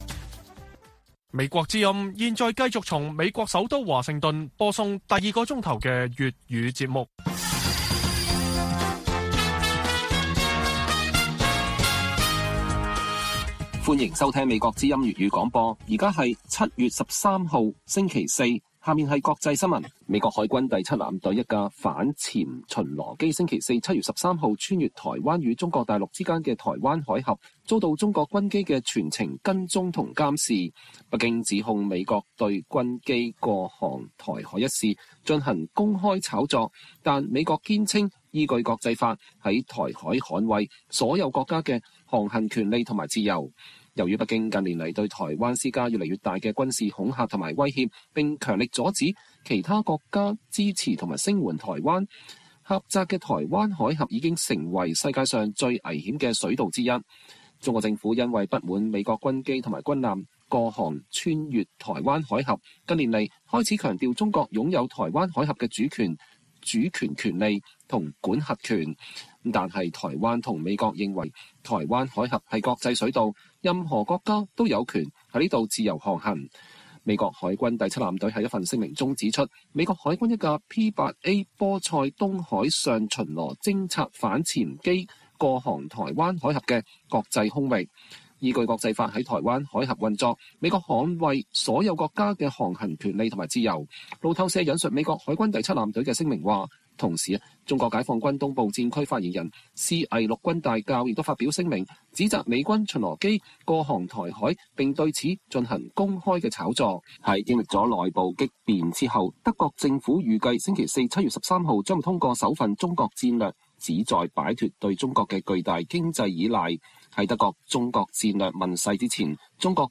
粵語新聞 晚上10-11點： 美國之音專訪白宮國安會戰略溝通協調員科比